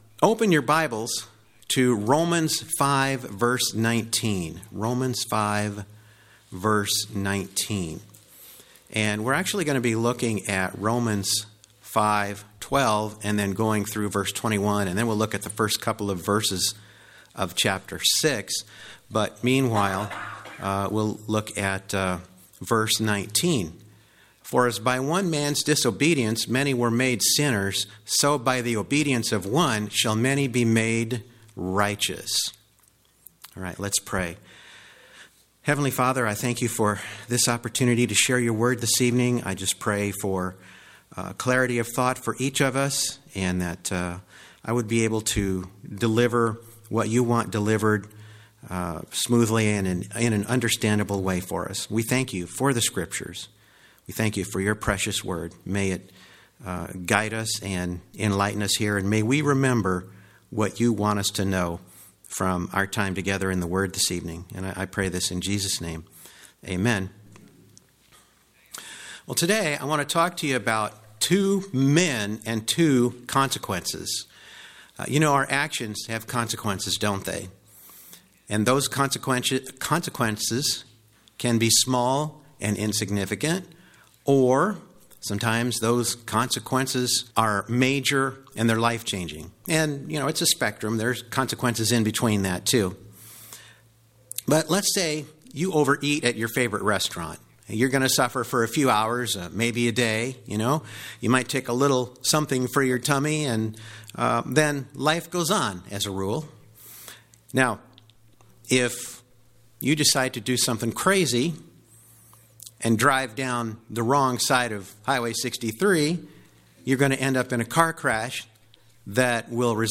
On Sunday evening, January 9, 2022, I had the privilege of delivering a message on the consequences of Adam’s disobedience and Christ’s obedience at our church–Northwood Baptist Church, Columbia, MO.